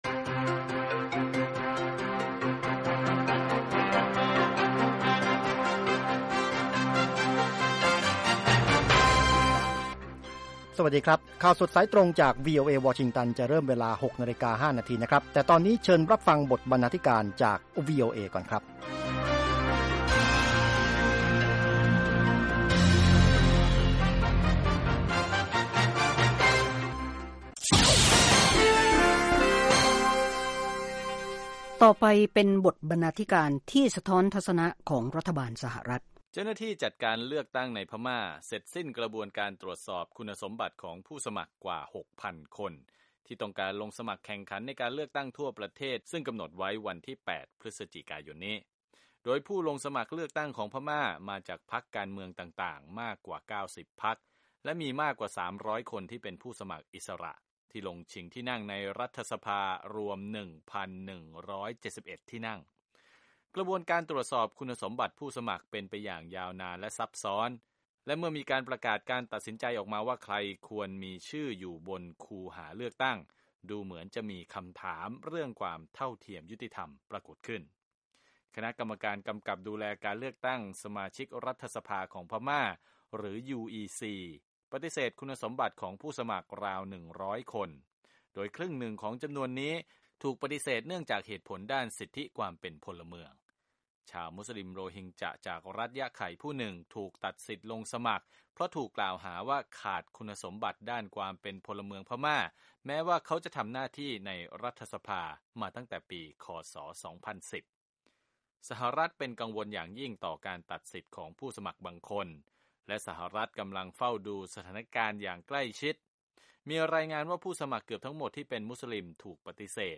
ข่าวสดสายตรงจากวีโอเอ ภาคภาษาไทย 6:00 – 6:30 น. วันจันทร์ 28 ก.ย. 2558